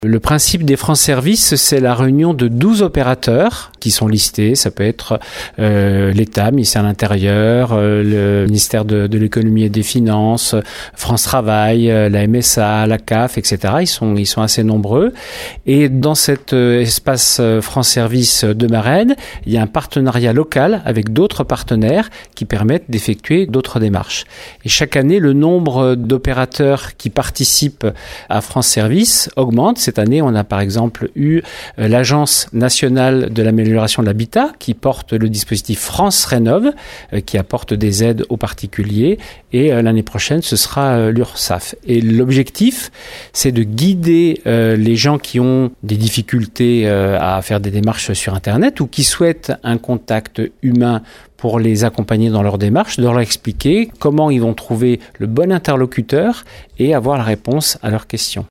L’objectif étant de proposer à tous les citoyens un guichet unique des services de l’État à moins de 20 minutes de leur lieu de domicile, afin de les accompagner dans leurs démarches de la vie quotidienne et administratives. Stéphane Donnot, sous-préfet de Rochefort, nous en rappelle le principe :